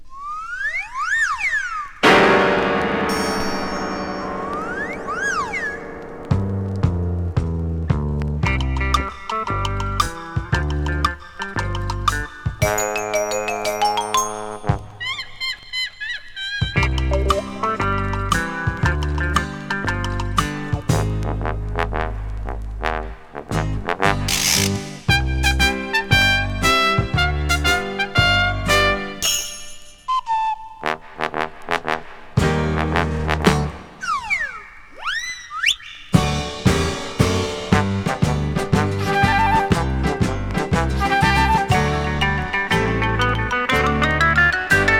用途に応じて様々な音仕掛け。タンゴ有り、カントリー有り、秘境有り、ほんわか有り?!と、次から次へと聴き手を刺激。